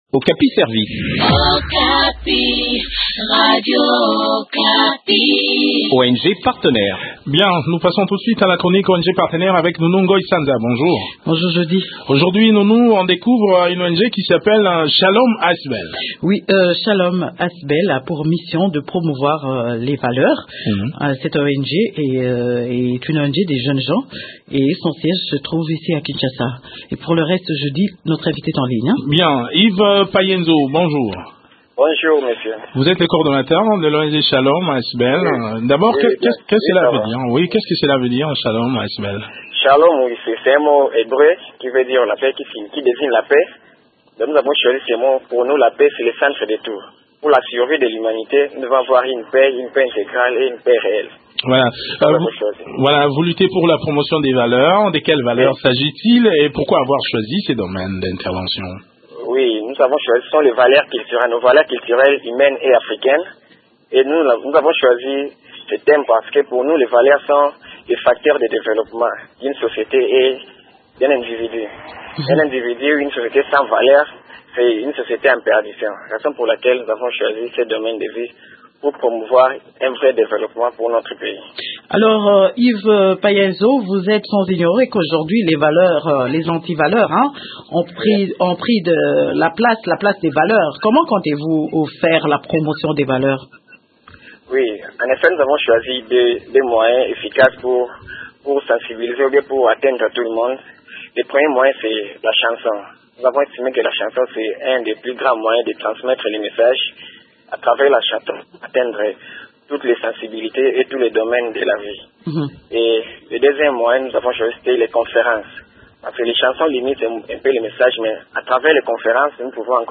Il présente son organisation dans cet entretien